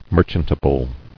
[mer·chant·a·ble]